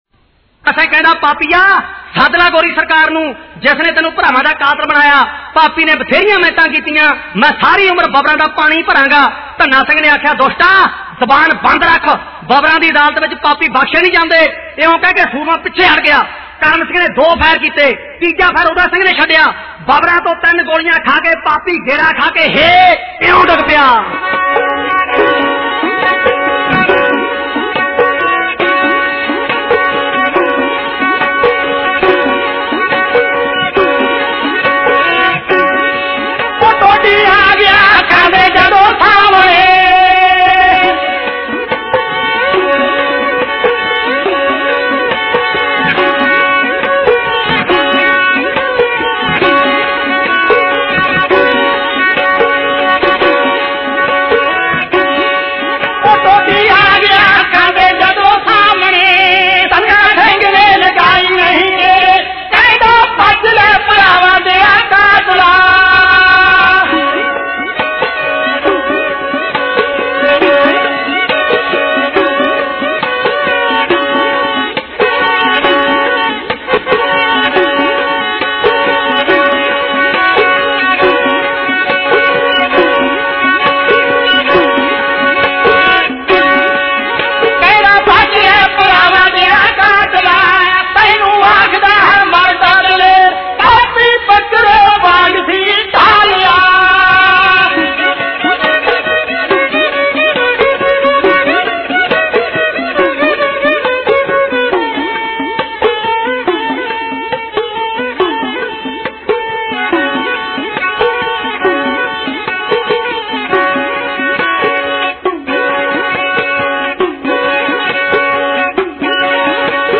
Dhadi Varan Album Info